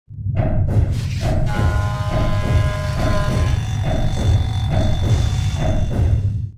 Repair1.wav